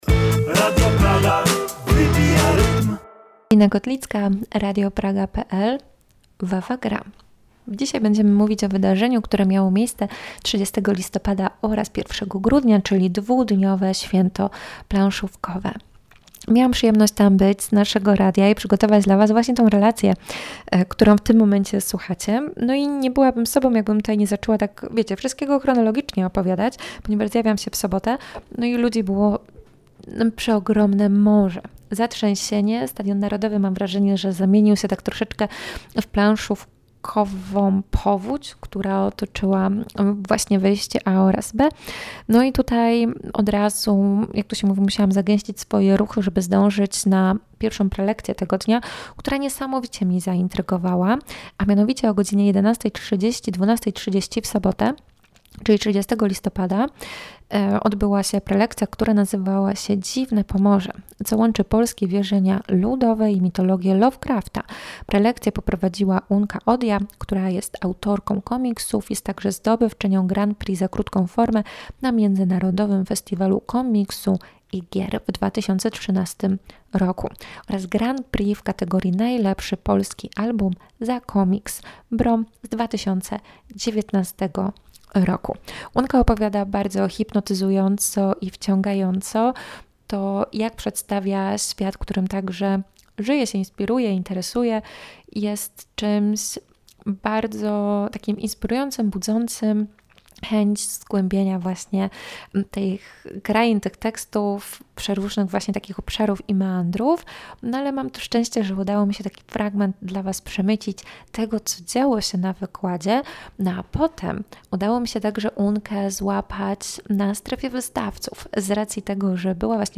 WawaGra 2024 na Stadionie Narodowym – zakończone, lecz niezapomniane!
Posłuchaj naszej relacji i poczuj niezwykłą atmosferę tego wydarzenia.